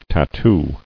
[tat·too]